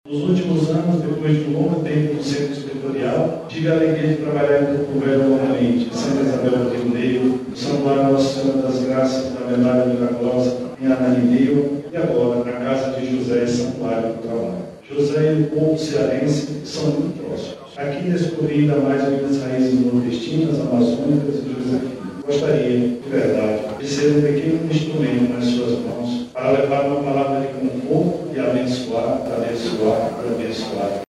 Em discurso emocionado, ele relembrou sua trajetória missionária e ressaltou a vontade de continuar servindo à comunidade.